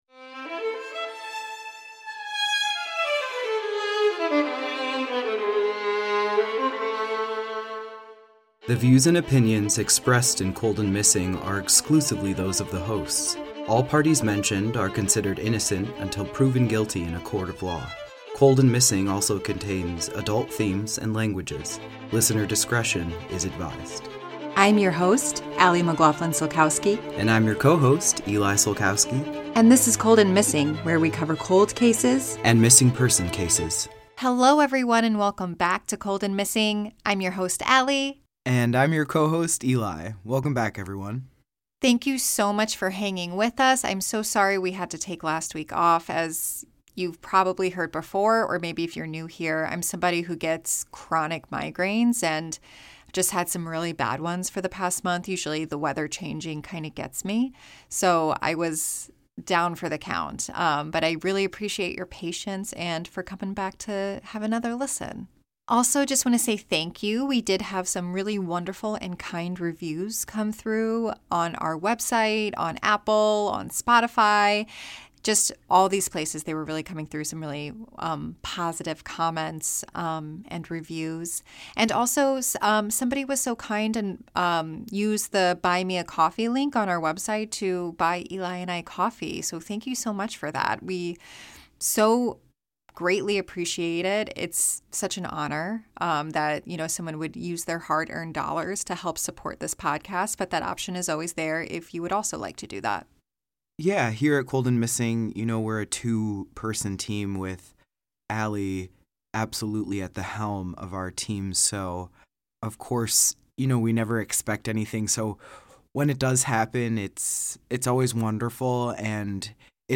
a husband and wife duo- will bring you either a Cold Case or a unresolved missing person.